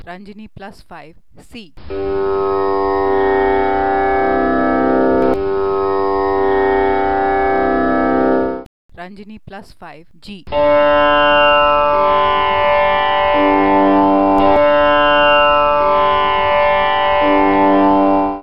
[woocs show_flags=0 width='130px' flag_position='right' txt_type='desc']The Radel Mini Miraj digital tanpura is a compact version of the Miraj Plus tanpura. It has the same amazing sound, so close to a traditional tanpura, that professional Indian classical musicians and teachers have overwhelmingly pronounced it to be the ultimate digital tanpura.